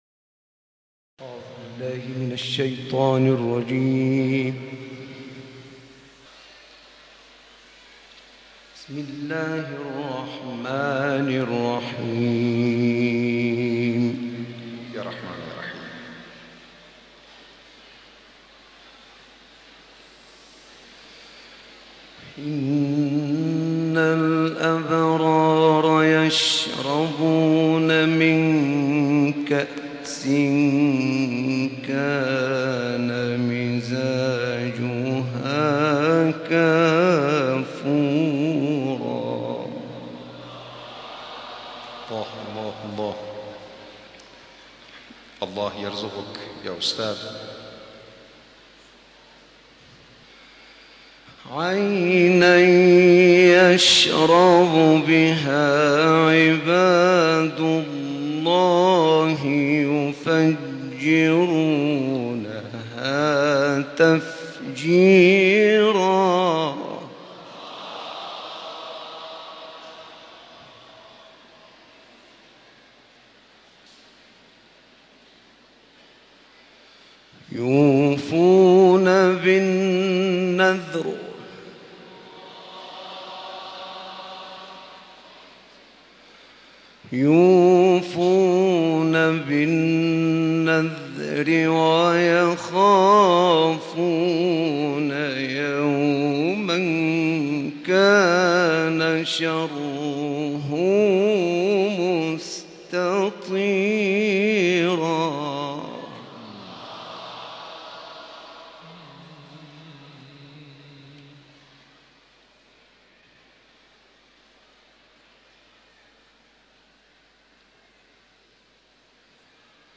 گروه فعالیت‌های قرآنی: قطعه‌ای زیبا از تلاوت‌ استاد نعینع از سوره انسان، آیات ۵ تا ۳۱ و سوره‌های ضحی و شرح ارائه می‌شود.